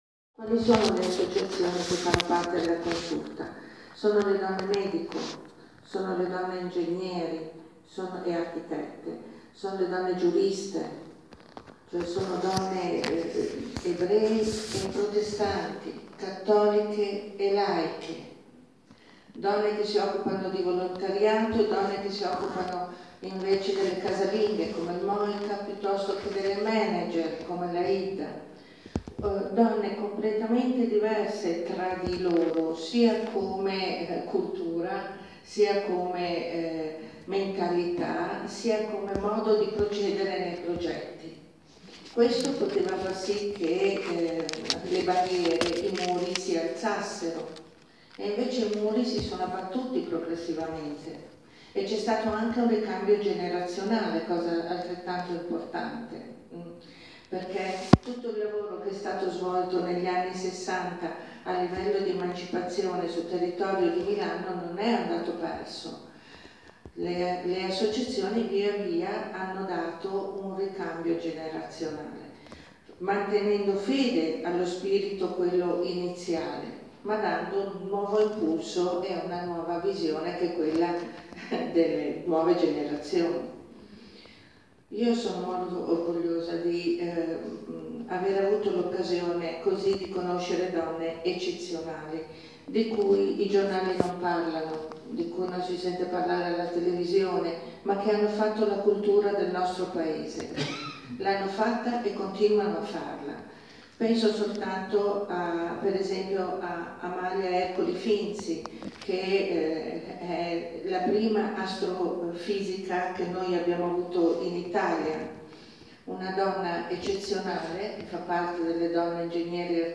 Il 27 e 28 marzo 2018, Il Sextante, l’Associazione Aurora e le reti femminili Consulta Interassociativa Femminile di Milano e Associazione DILA, hanno offerto ad un pubblico folto e interessato due serate dedicate alle donne, al lavoro e alla presentazione dell’opera teatrale Crepuscolo a Mitilene.